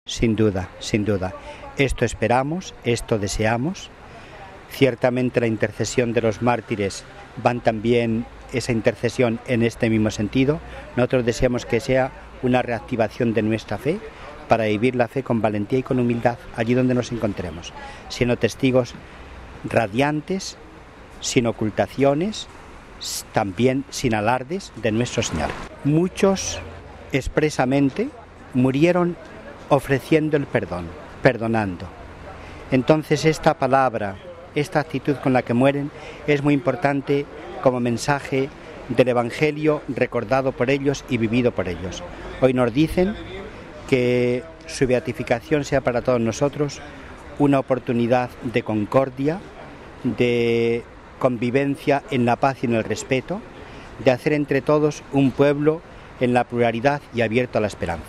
Finalizada la plegaria mariana nuestros micrófonos recogieron el testimonio del Obispo de Bilbao y Presidente de la Conferencia Episcopal española, Mons. Ricardo Blázquez.